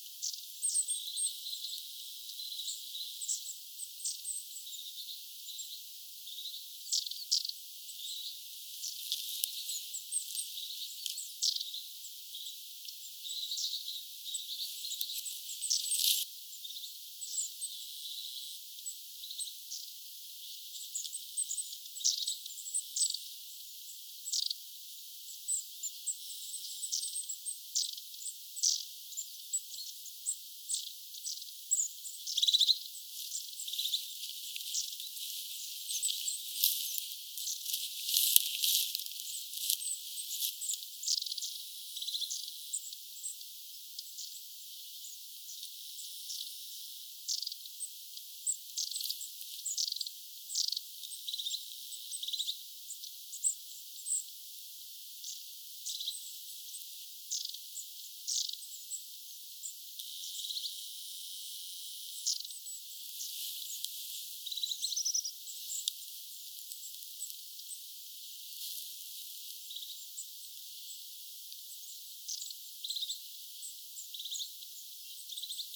pyrstötiaisparvi kuukkeliruokinnan lähellä
pyrstotiaisparvi_kuukkeliruokinnan_lahella.mp3